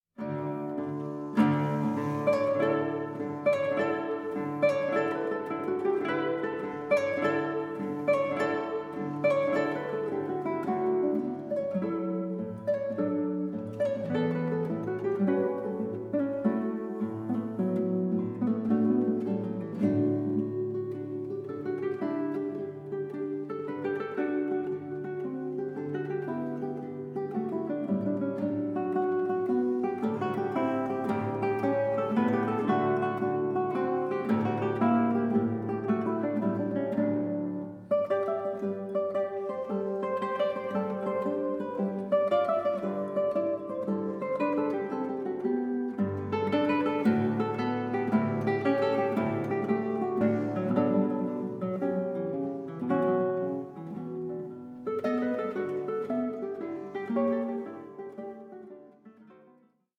INTROSPECTIVE EXPLORATION OF FRENCH-SPANISH MUSICAL BORDERS